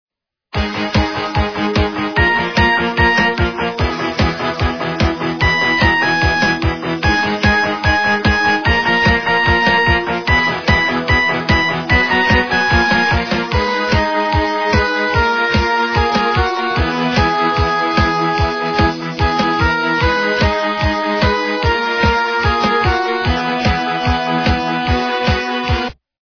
- рок, металл
качество понижено и присутствуют гудки